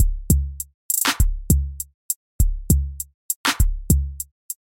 维布节拍
描述：震动的节拍
标签： 100 bpm Hip Hop Loops Drum Loops 827.02 KB wav Key : D
声道立体声